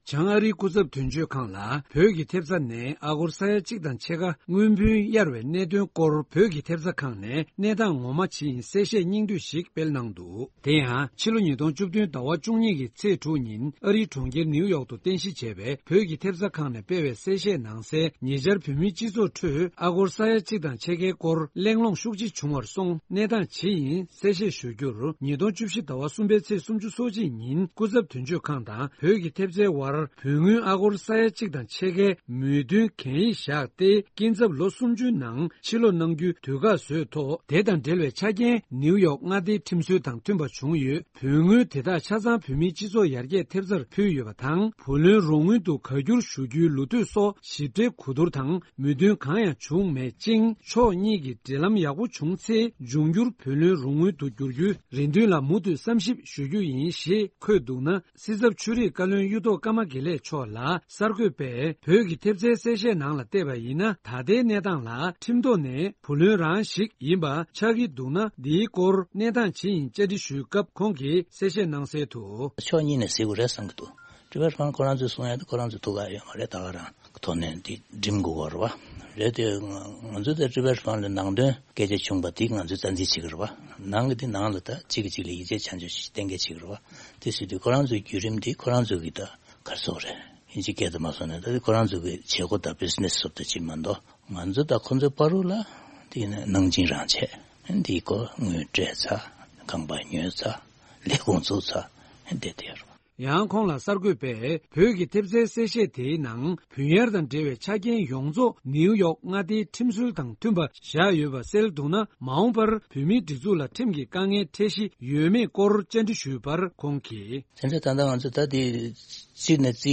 བོད་ཀྱི་ཐེབས་རྩ་ཁང་གི་གསལ་བཤད་ཐད་སྲིད་ཚབ་མཆོག་ལ་བཅར་འདྲི་ཞུས་པ།
སྒྲ་ལྡན་གསར་འགྱུར།